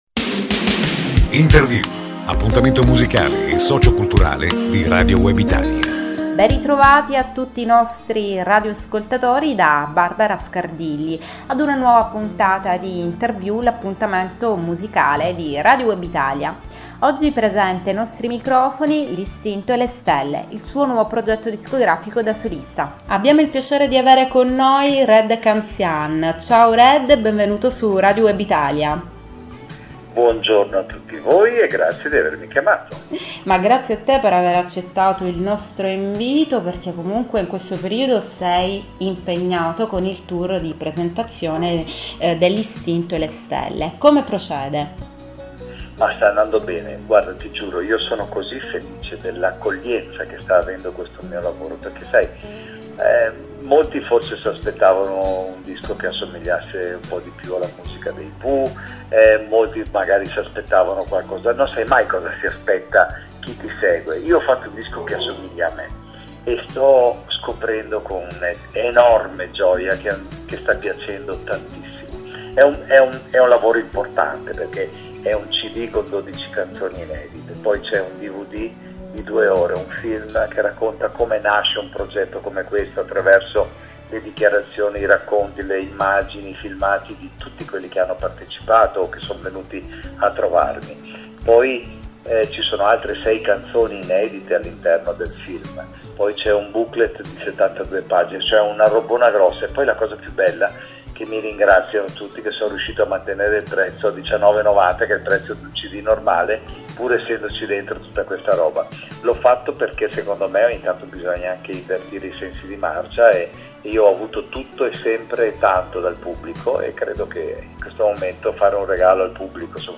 Di questo e di tanto altro, ne parleremo insieme a RED CANZIAN , che sarà ospite dei microfoni di Radio Web Italia, venerdi' 10 ottobre alle ore 12.00.
Red-Canzian-Intervista.mp3